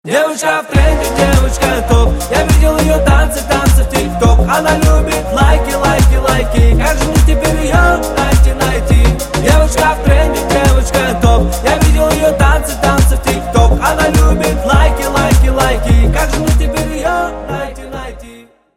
поп
ритмичные
Хип-хоп
зажигательные
веселые